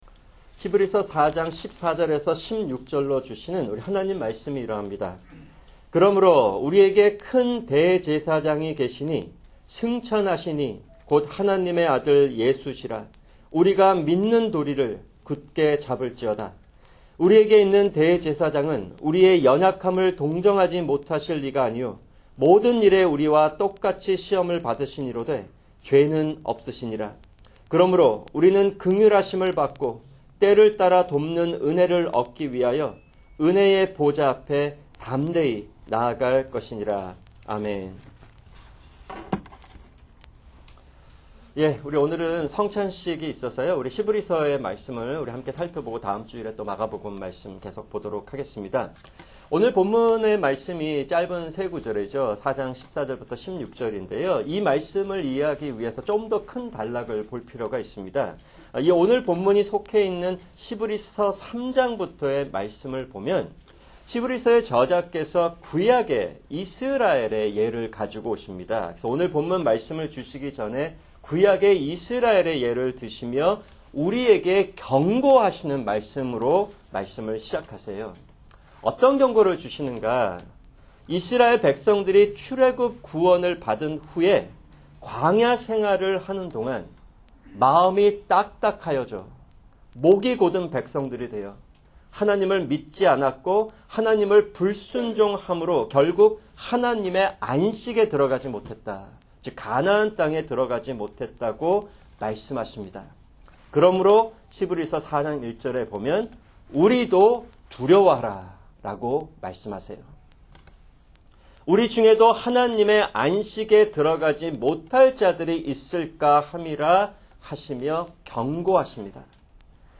[주일 설교] 히브리서 4:14-16